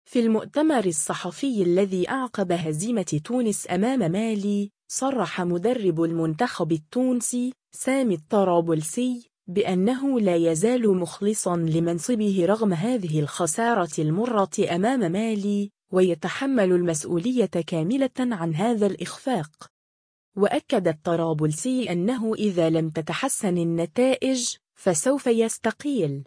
في المؤتمر الصحفي الذي أعقب هزيمة تونس أمام مالي، صرّح مدرب المنتخب التونسي، سامي الطرابلسي، بأنه “لا يزال مخلصًا لمنصبه رغم هذه الخسارة المُرّة أمام مالي، ويتحمل المسؤولية كاملةً عن هذا الإخفاق”.